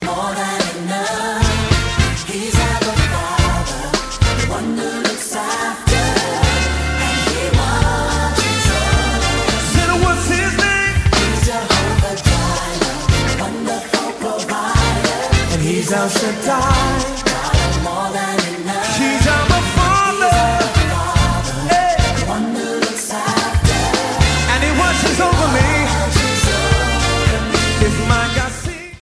Christian & Gospel RingTones